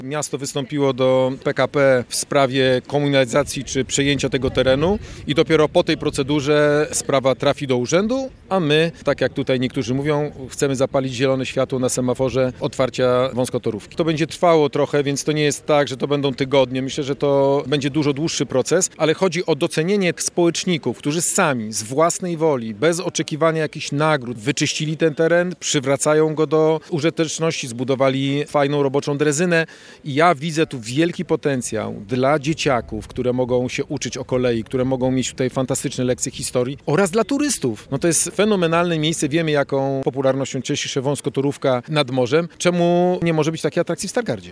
O istocie tego przedsięwzięcia oraz planach na przyszłość mówili Wojewoda Zachodniopomorski Adam Rudawski oraz Prezydent Stargardu Rafał Zając podczas wspólnego briefingu.